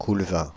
Coulvain (French pronunciation: [kulvɛ̃]
Fr-Coulvain.ogg.mp3